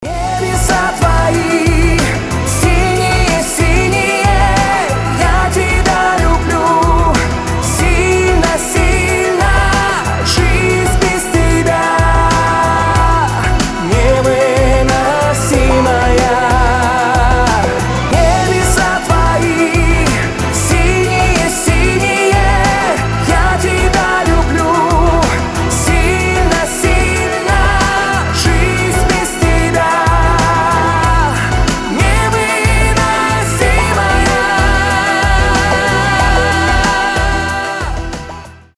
• Качество: 320, Stereo
поп
громкие